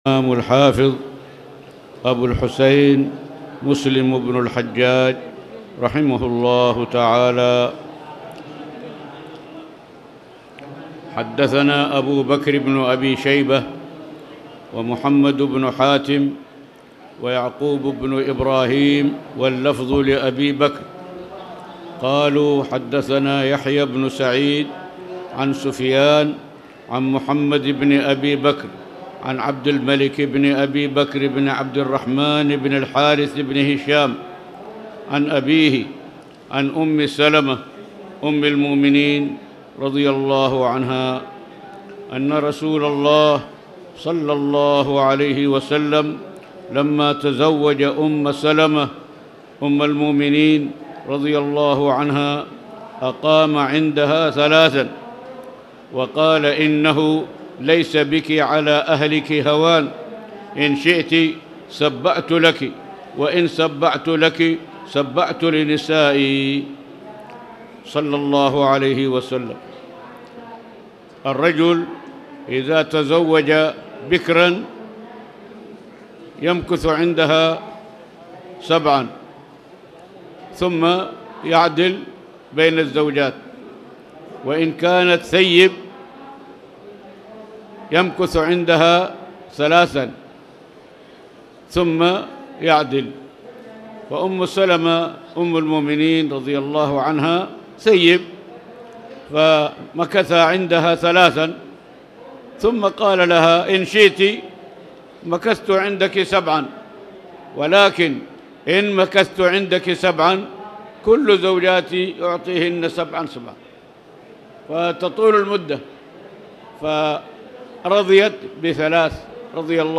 تاريخ النشر ١٣ ربيع الأول ١٤٣٨ هـ المكان: المسجد الحرام الشيخ